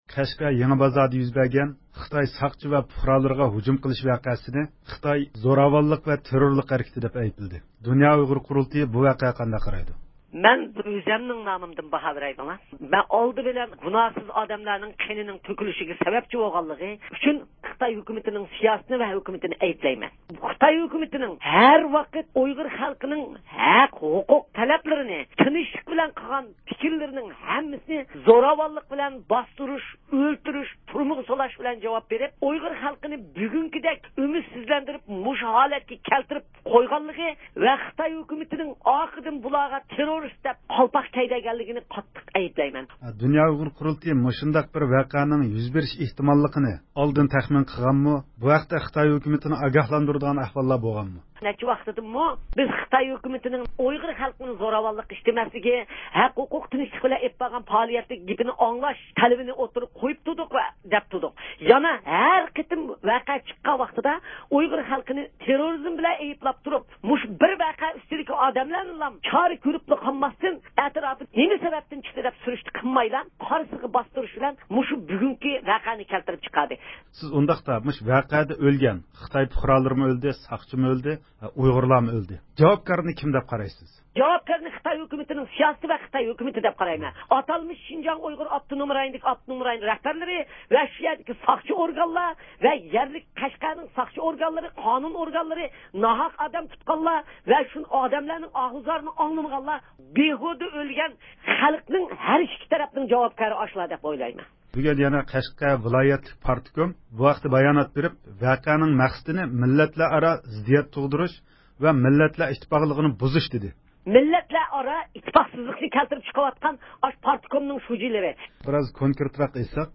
ئۇيغۇر مىللىي ھەرىكىتىنىڭ رەھبىرى رابىيە قادىر خانىم رادىئومىز زىيارىتىنى قوبۇل قىلىپ، خىتاينىڭ ھۇجۇمچىلار ئۈستىدىكى ئەيىبلەشلىرىنى رەت قىلدى.